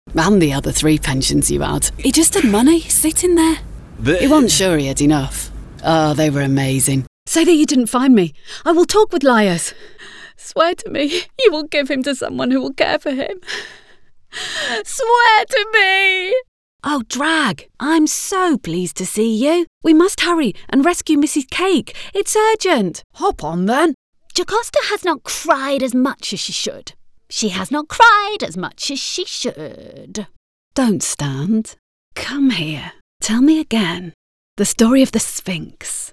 English (British)
Distinctive, Versatile, Friendly